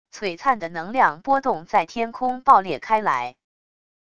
璀璨的能量波动在天空爆裂开来wav音频